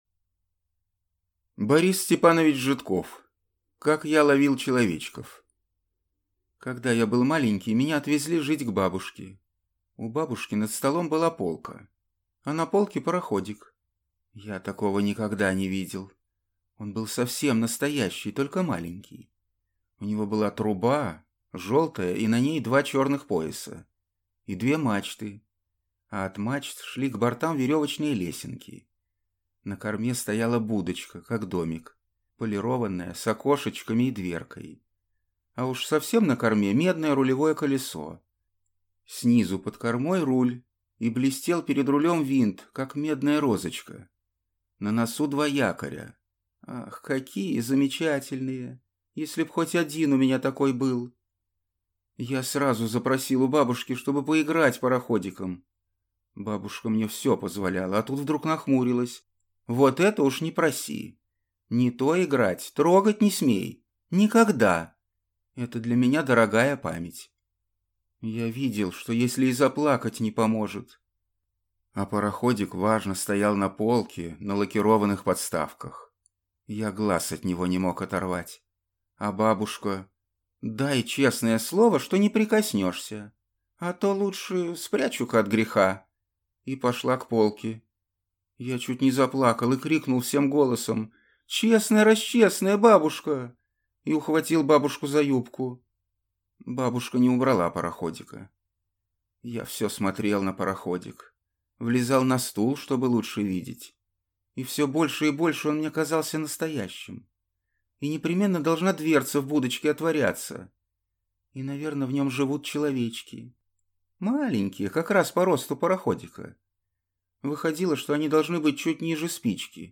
Аудиокнига Как я ловил человечков | Библиотека аудиокниг